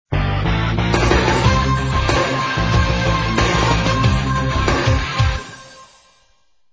The recycled jingle collection: